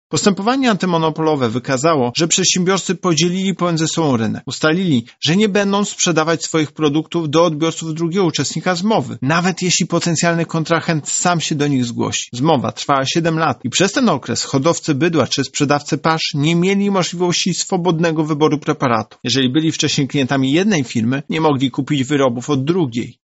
Zmowa dotyczyła sprzedaży mieszanek używanych do karmienia młodych zwierząt. O nieuczciwym zachowaniu przedsiębiorców mówi prezes UOKiK Tomasz Chróstny.